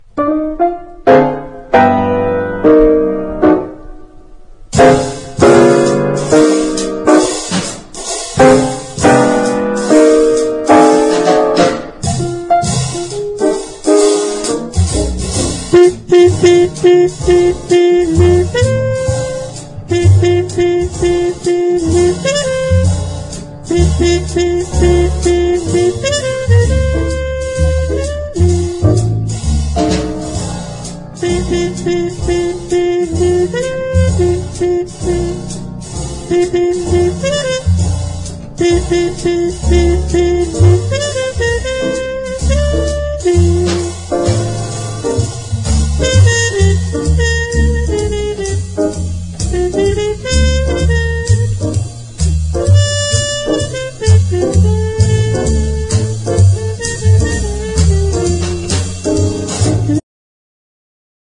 BIG BAND JAZZ
洒脱なモダン・スウィング